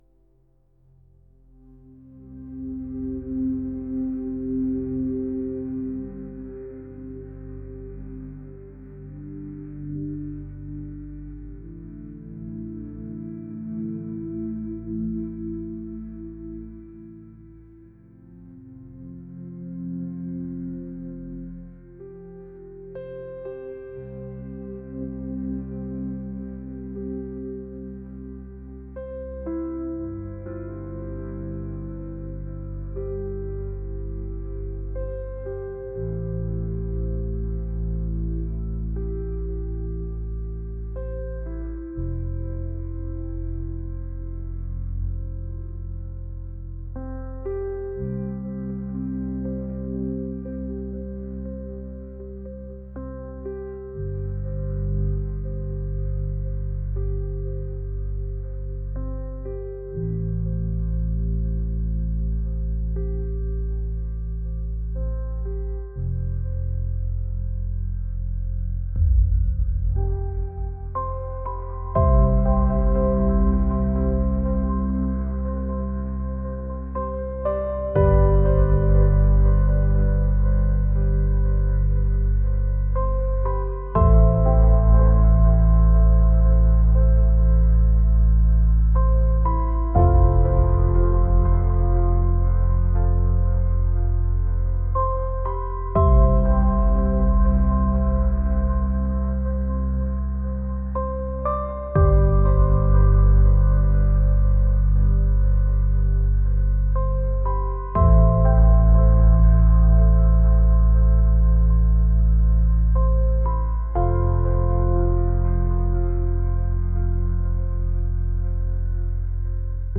atmospheric | ambient | ethereal